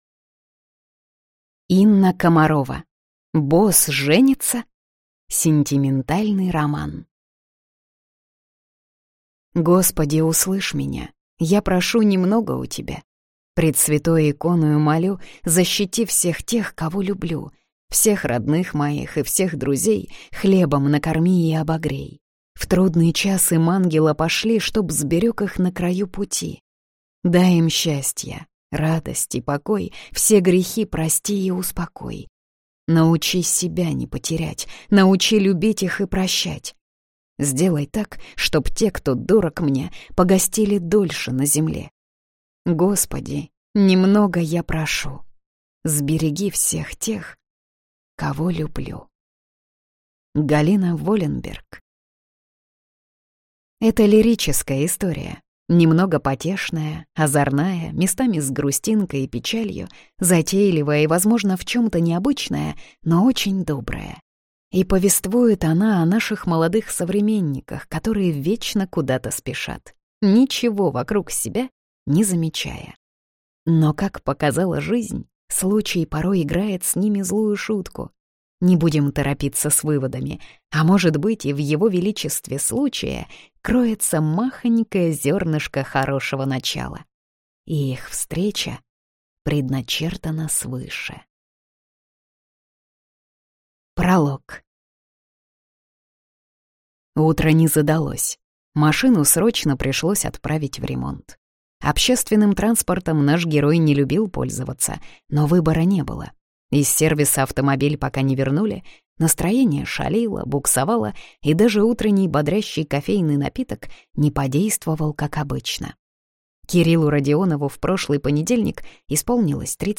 Аудиокнига Босс женится?!